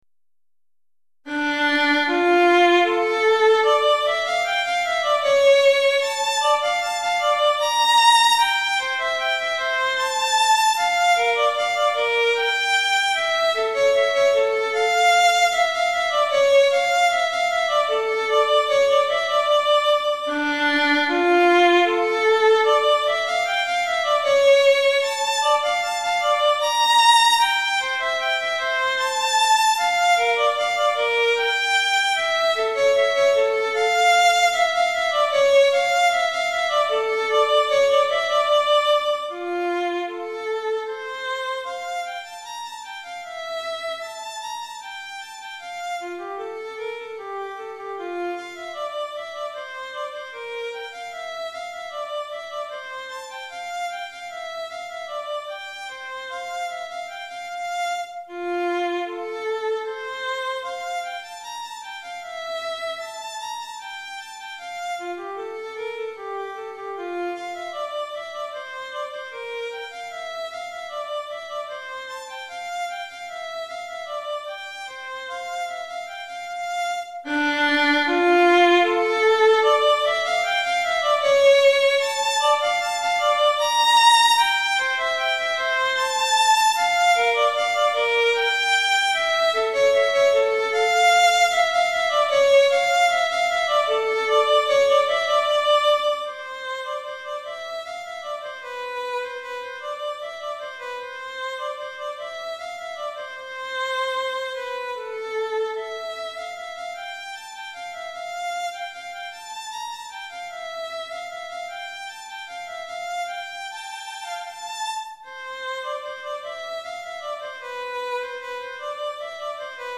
Violon Solo